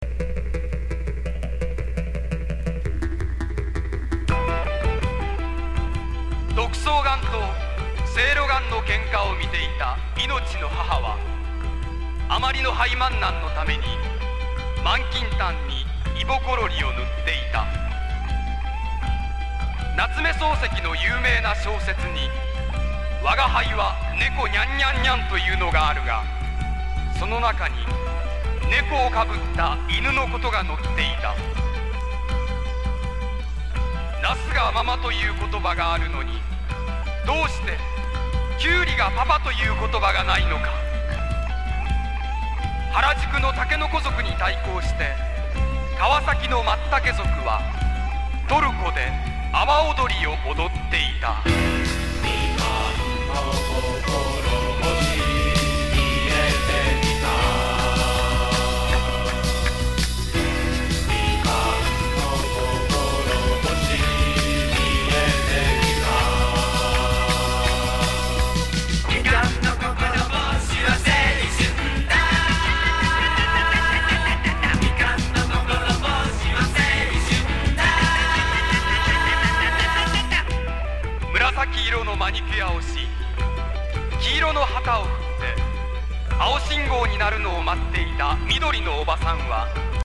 テクノ歌謡・コミックソング名曲!!
ポピュラー# TECHNO POP